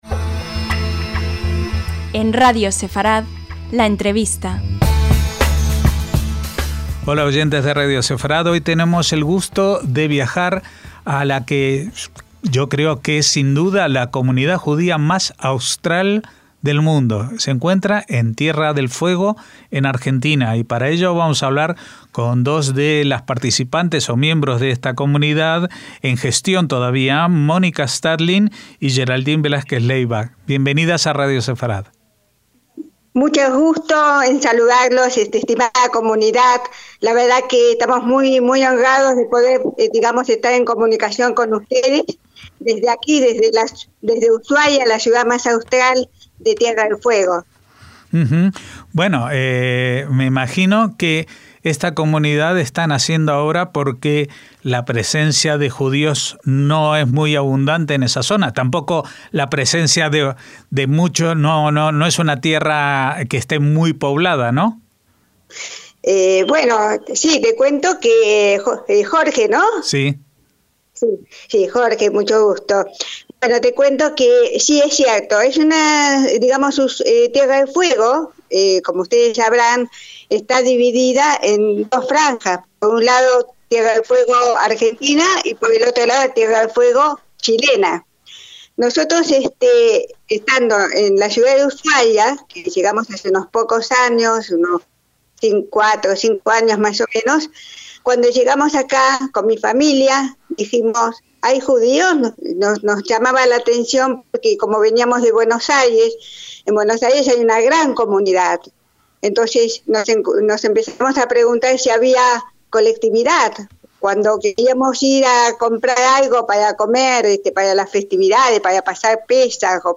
LA ENTREVISTA - Tierra del Fuego es el punto más austral del continente americano y de cualquier otro, a excepción de la Antártida.